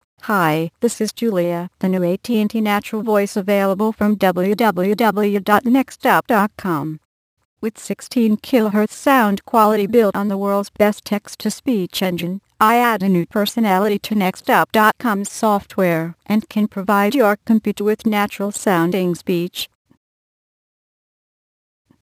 Texte de d�monstration lu par Julia (AT&T Natural Voices; distribu� sur le site de Nextup Technology; femme; anglais)